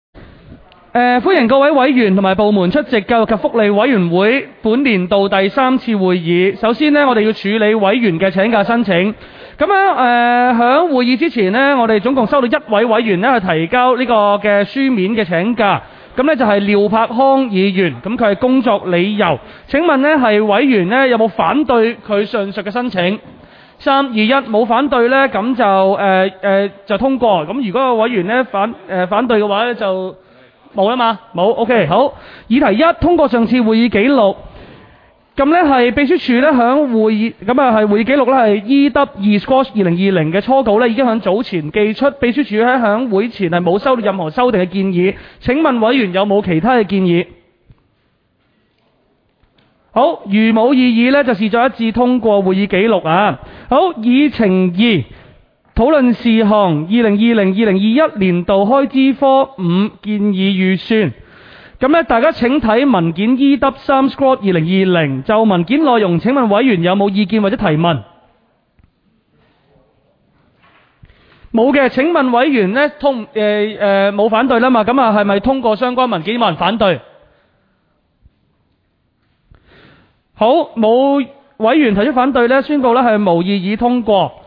委员会会议的录音记录
地点: 区议会会议室